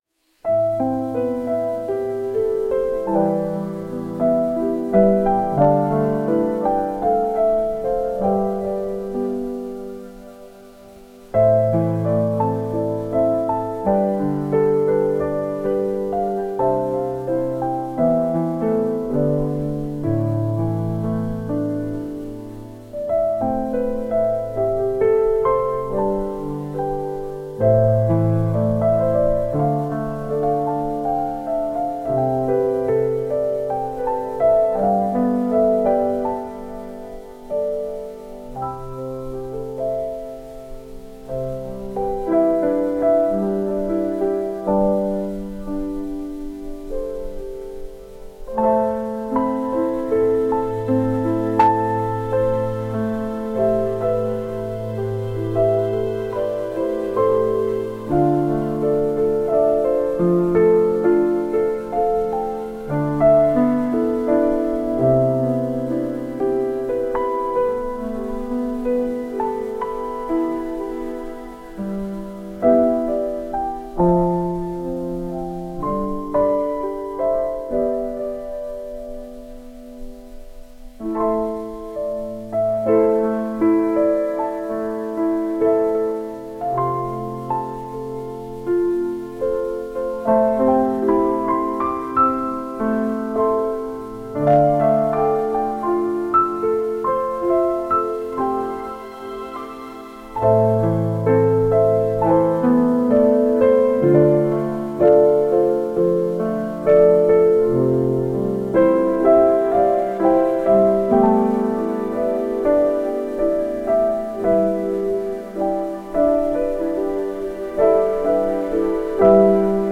おしゃれ
大人な雰囲気